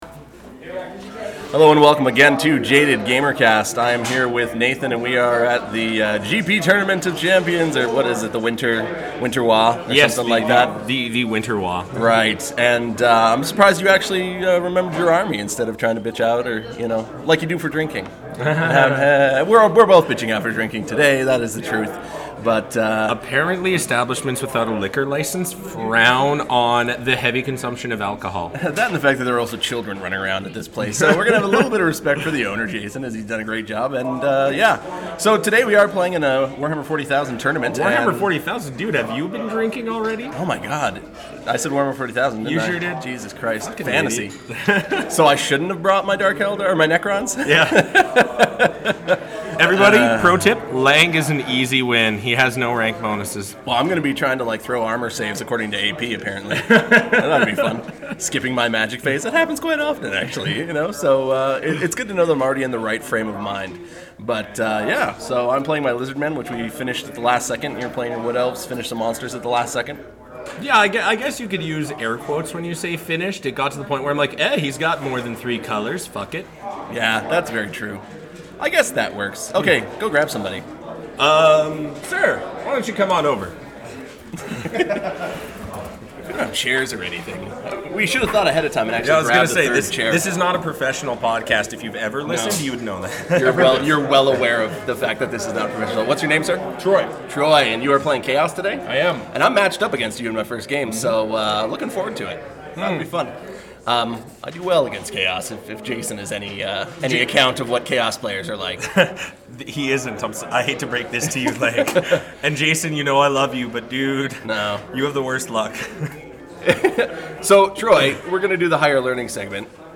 Live-ish from the Winter Waaagh! Tournament in GP Town in the country of Countrystan!!